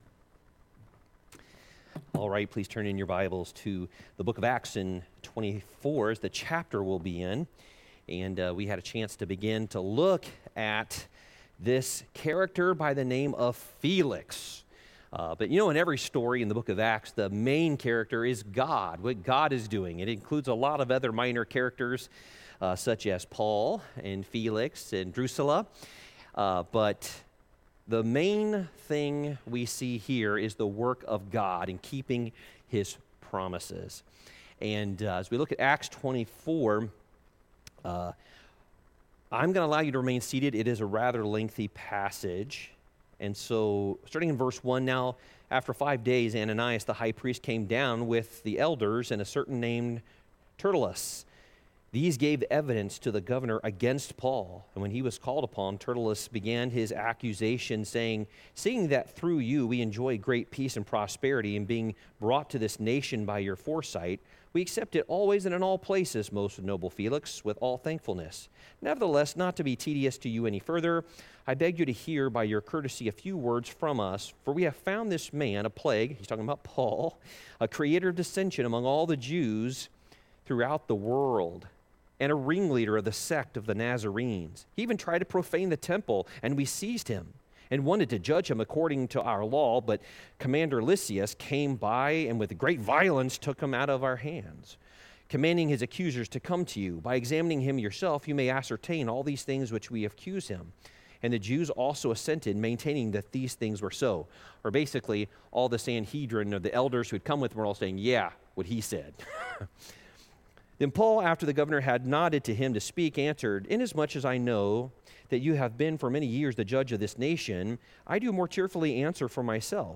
Worship Service 01/07/2024